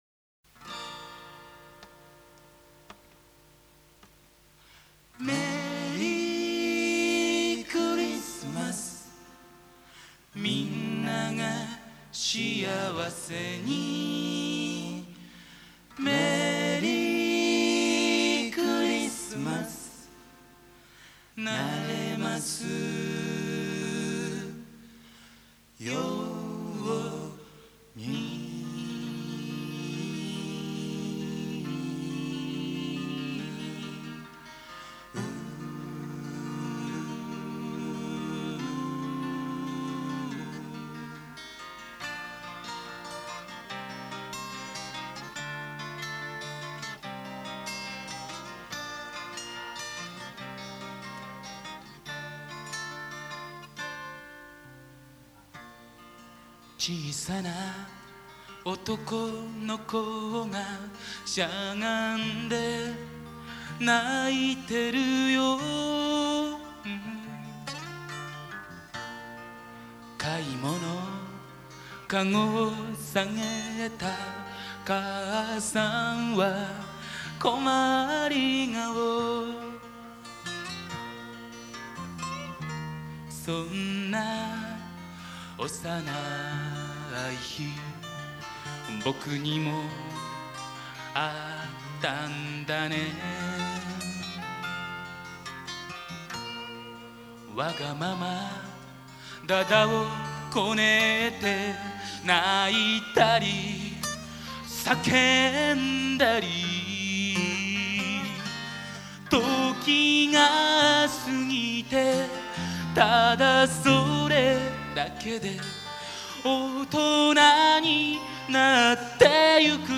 場所：神奈川公会堂
イベント名：横浜ふぉーく村コンサート